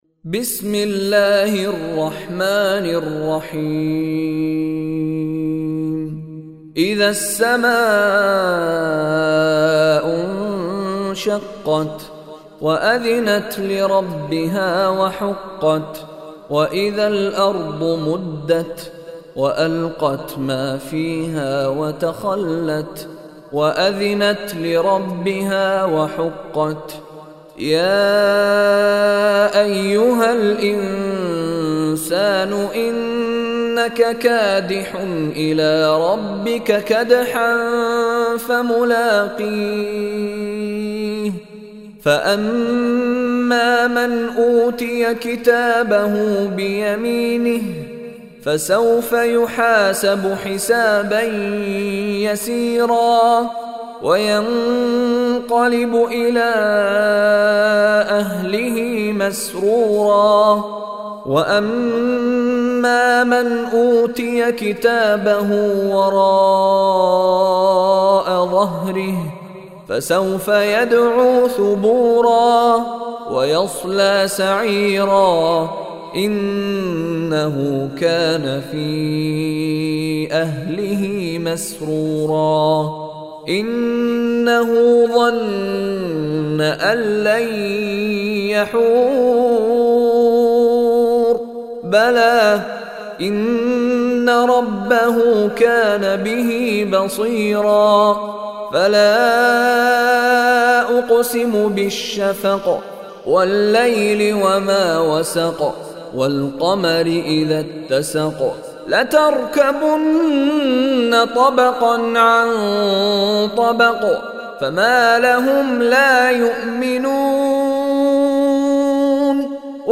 Listen online and download beautiful Quran tilawat / Recitation of Surah Al-Inshiqaq in the beautiful voice of Sheikh Mishary Rashid Alafasy.